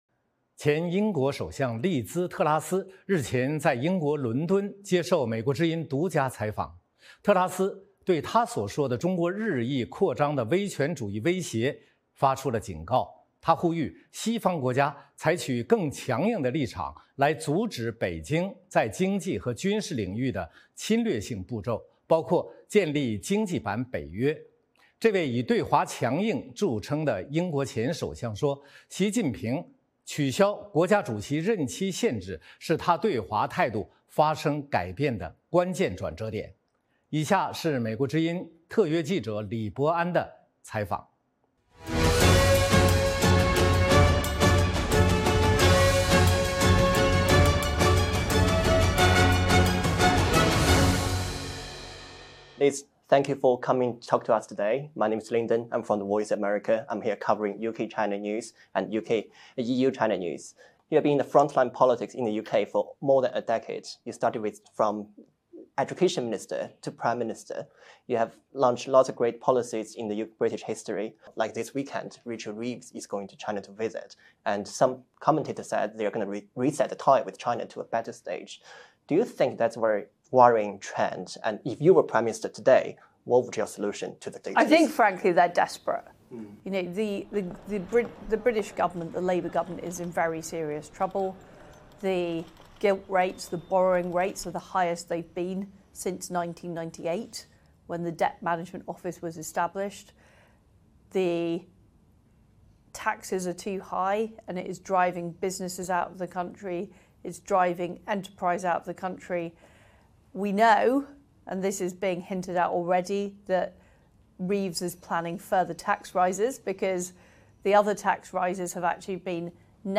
专访前英国首相特拉斯：应对中国的唯一方式是展示经济和军事实力
前英国首相利兹·特拉斯接受美国之音专访，她认为中国日益扩张的威权主义威胁值得关注，呼吁西方以更强硬的立场阻止北京在经济和军事领域的侵略性步骤，包括建立“经济版北约”。这位以对华强硬著称的英国前首相说，习近平取消国家主席任期限制是她对华态度转变的关键。